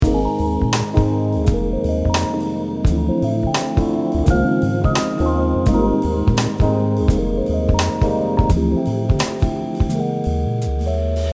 • High-quality audio backing track
🎵 Style: R&B
🎵 Key Center: Ab Major
🎵 Tempo: 90 BPM
🎵 Time Signature: 4/4
🎵 Mood: Chill, groovy, and laid-back